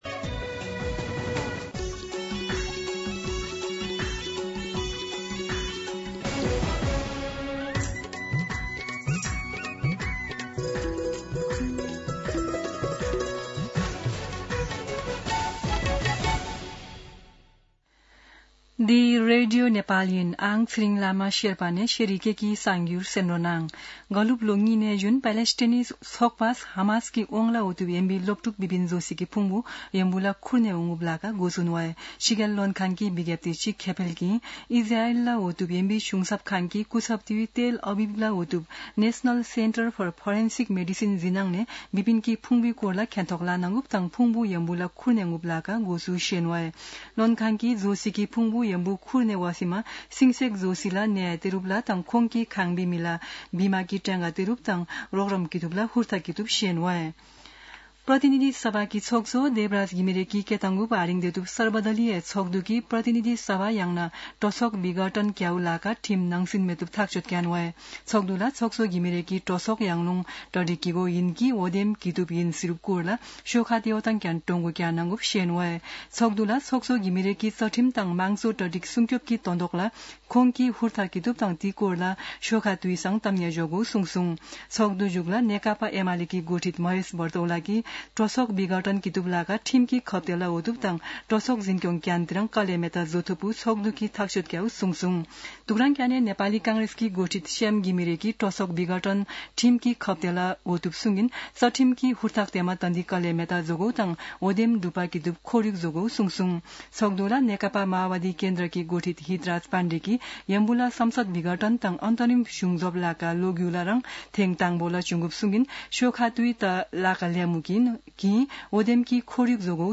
An online outlet of Nepal's national radio broadcaster
शेर्पा भाषाको समाचार : २९ असोज , २०८२
Sherpa-News-06-29.mp3